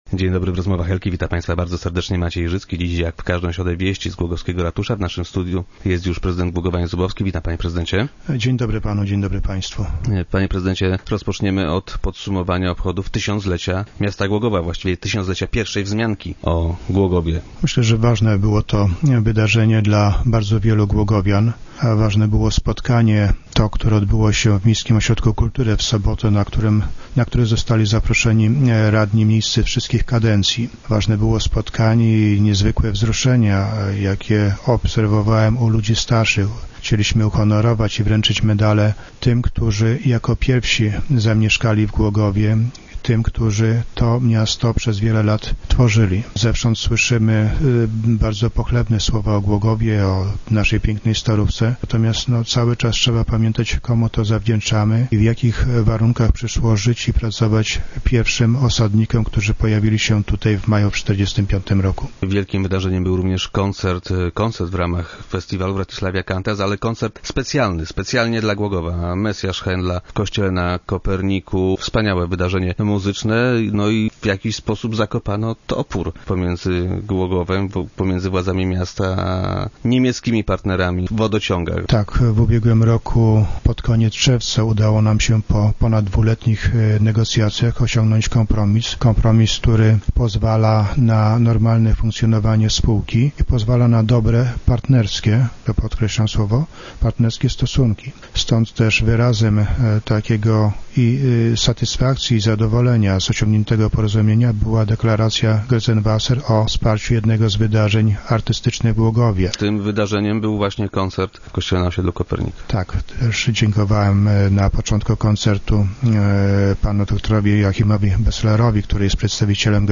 Powiat nie godzi się bowiem na wykonanie na swej działce odstojnika wody deszczowej. - Otrzymaliśmy oficjalne pismo z odmową - poinformował nas prezydent Jan Zubowski, który był dziś gościem Rozmów Elki.